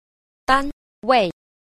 2. 單位 – dānwèi – đơn vị